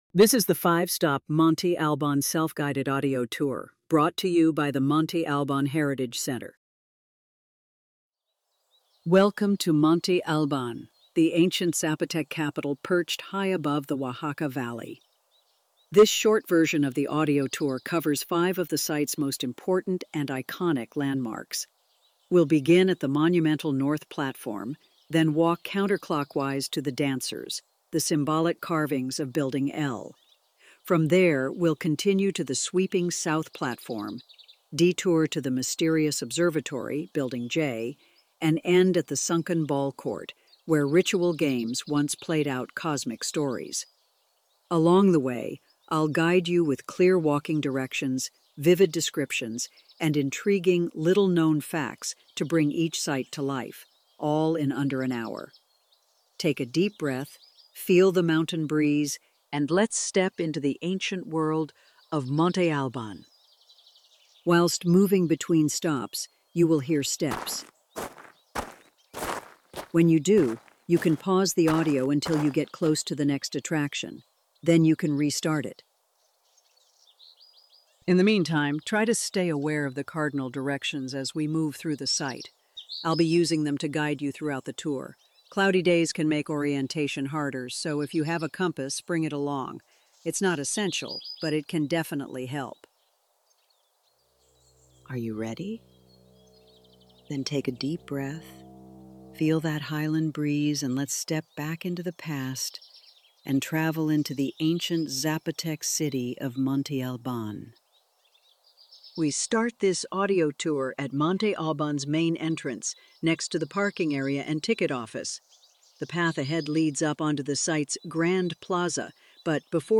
Immediate download of the 5 stop Self-Guided Tour